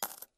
coin_coin_3.ogg